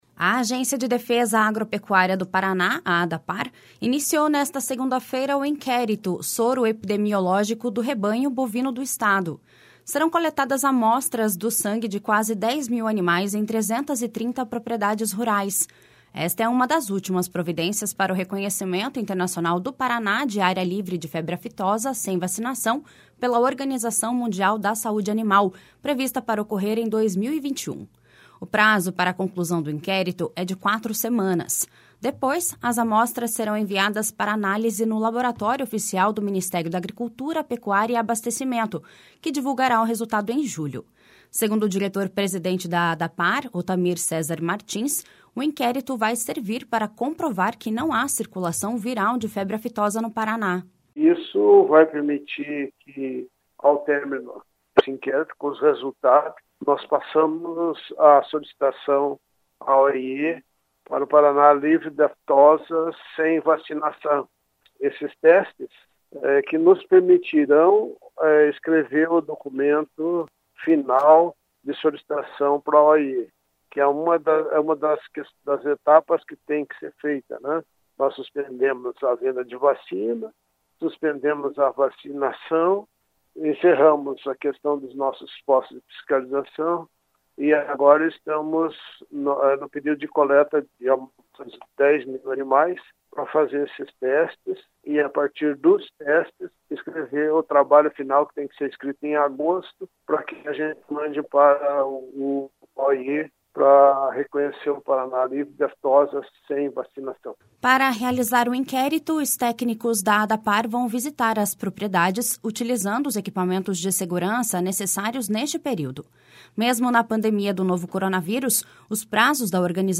Segundo o diretor-presidente da Adapar, Otamir Cesar Martins, o inquérito vai servir para comprovar que não há circulação viral de febre aftosa no Paraná.// SONORA OTAMIR CESAR MARTINS.//
De acordo com o secretário da Agricultura e Abstecimento, Norberto Ortigara, o novo status sanitário deverá ajudar a aumentar o protagonismo da produção paranaense no mercado internacional.// SONORA NORBERTO ORTIGARA.//